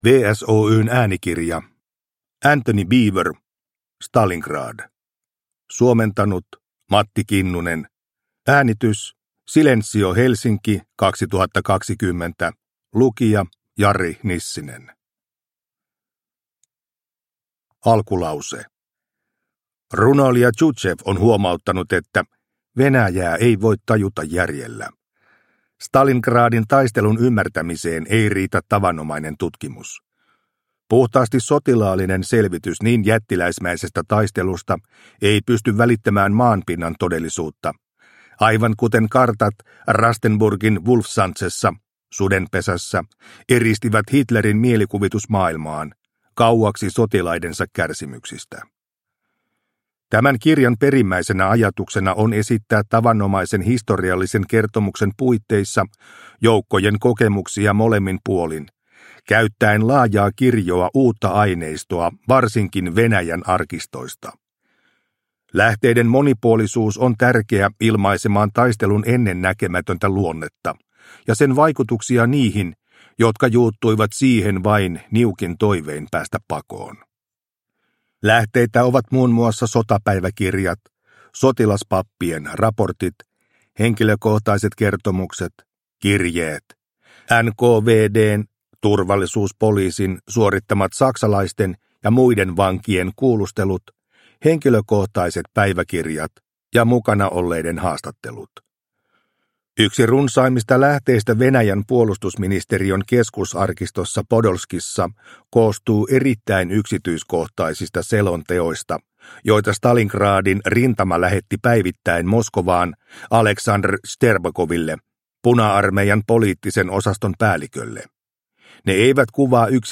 Stalingrad – Ljudbok – Laddas ner